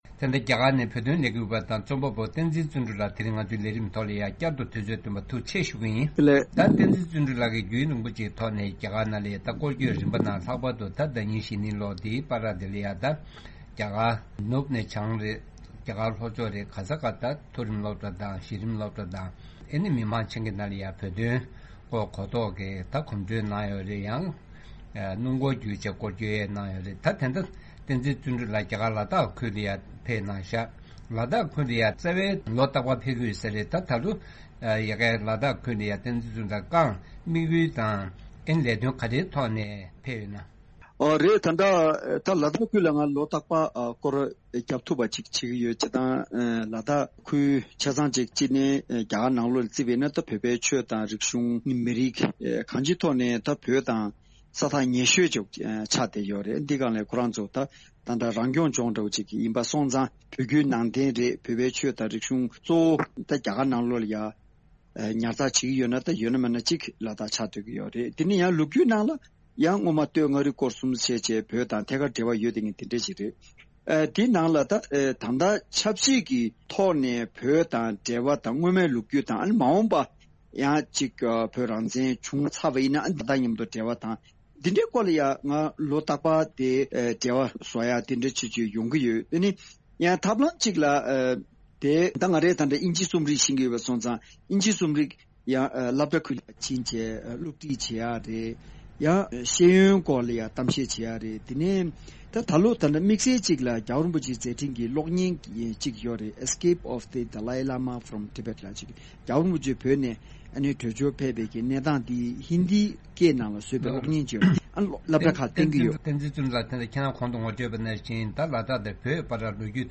གནས་འདྲི་ཞུས་པར་གསན་རོགས་གནང་།།